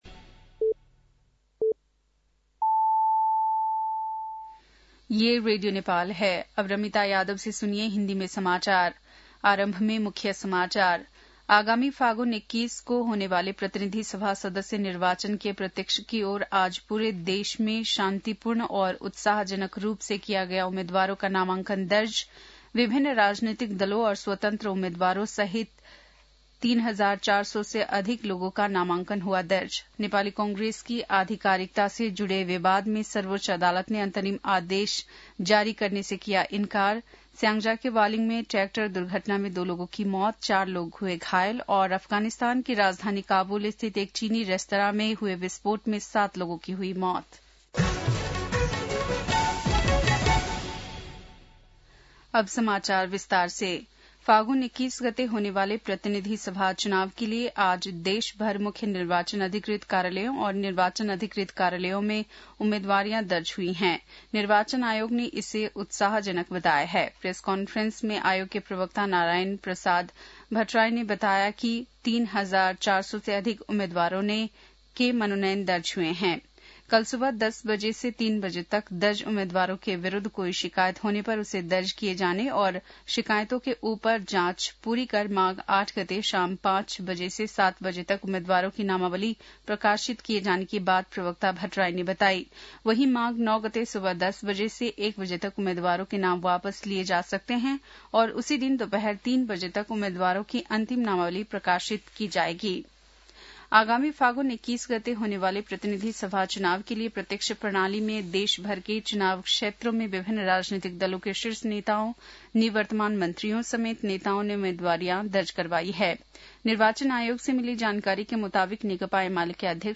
बेलुकी १० बजेको हिन्दी समाचार : ६ माघ , २०८२